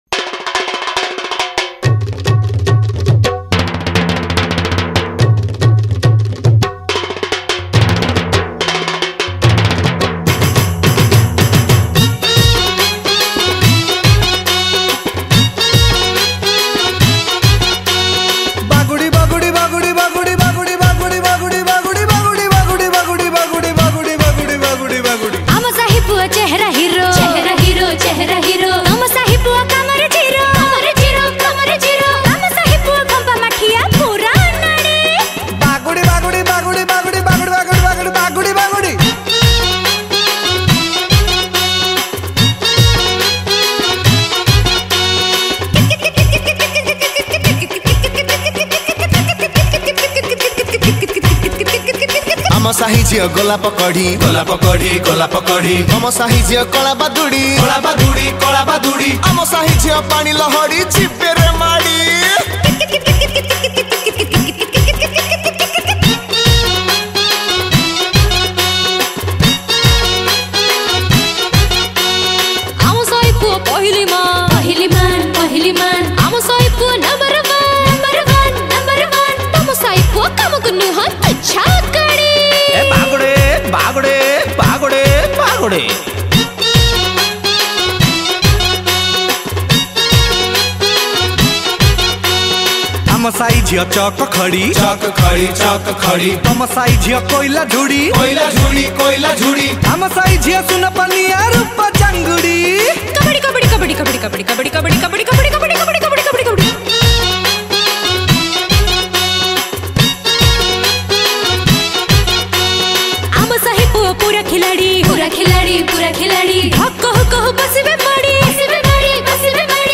Female Singers
Male Singers